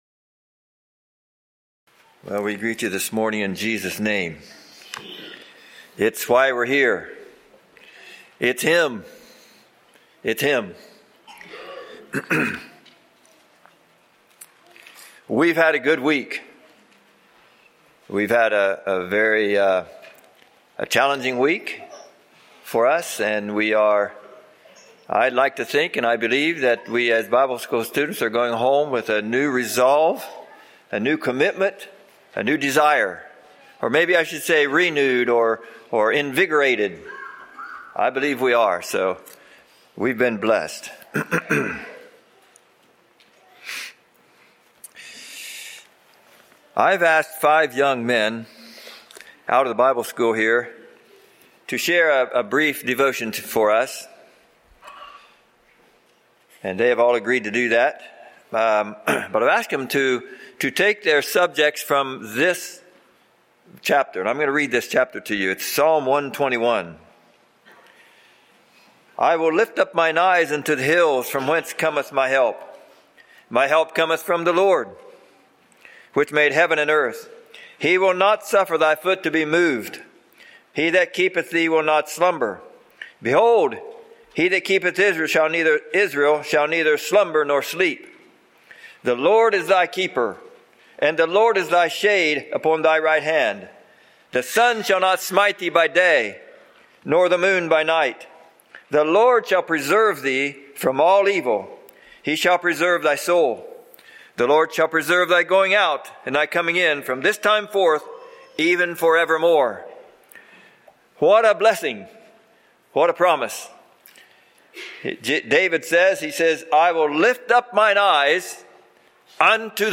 Living Hope | Sermon
Back to Sermon Archive Download **Categories:** Youth Bible School, Faith, Trust **Summary:** This opening meditation is by five young men who attended Youth Bible School.